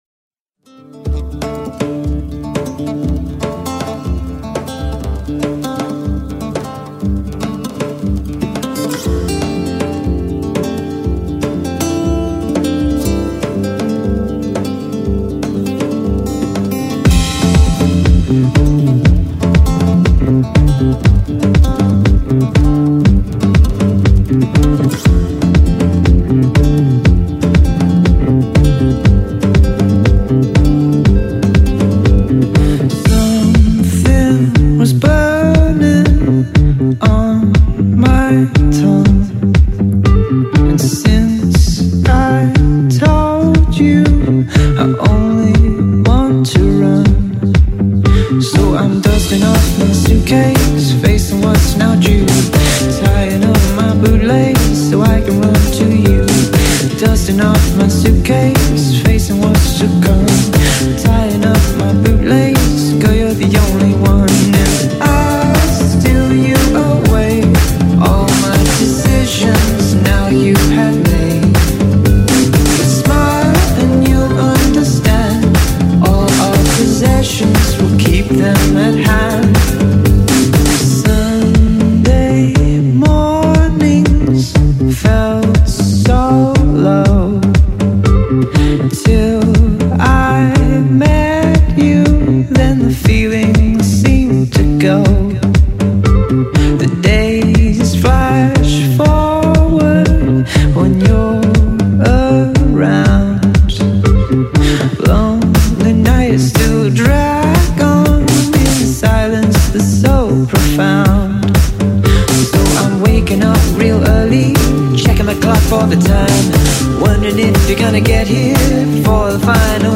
baroque pop band
write beautiful sample-based music